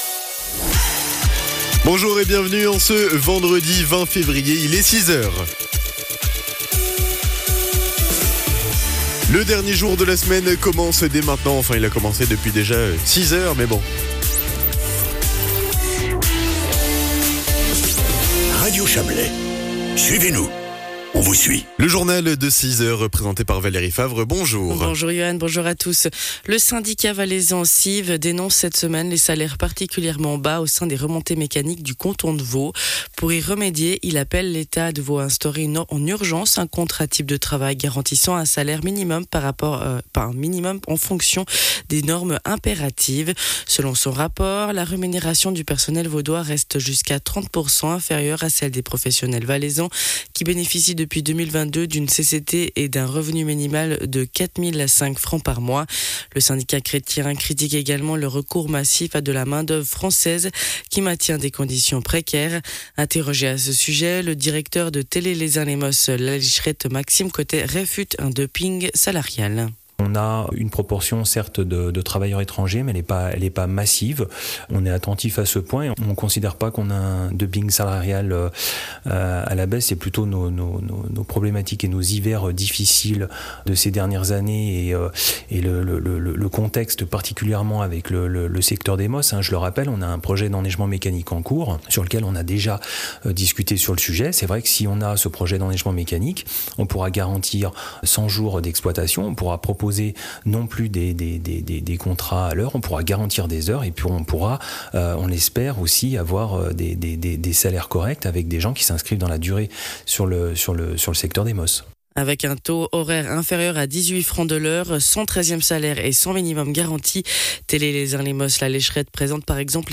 Le journal de 6h00 du 20.02.2026